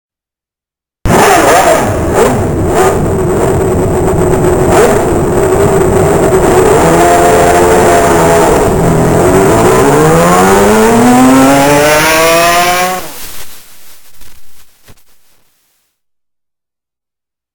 IWAYA ENGINE SOUND COLLECTION